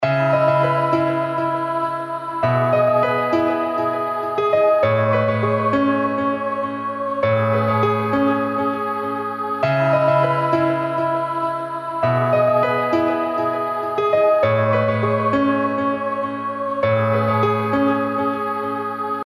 原声钢琴100bpm Fmajor
描述：F大调RnB钢琴循环曲...
Tag: 100 bpm RnB Loops Piano Loops 1.62 MB wav Key : F